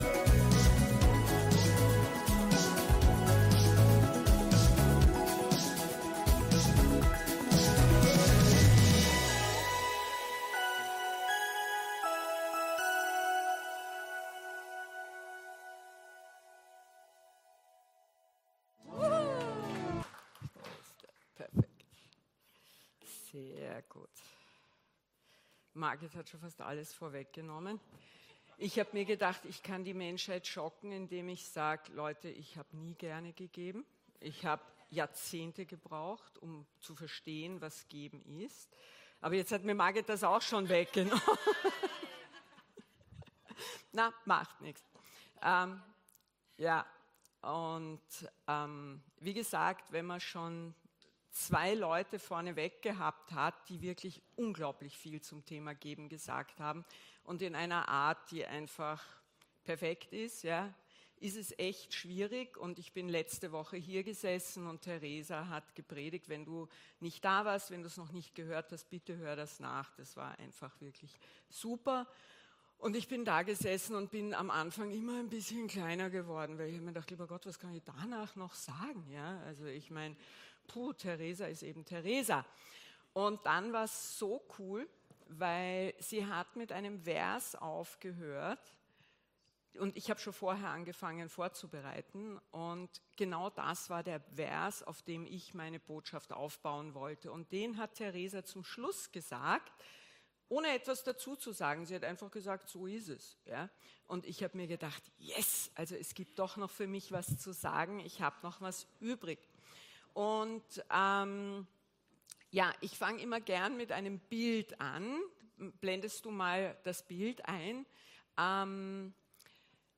Gottesdienst live aus der LIFE Church Wien.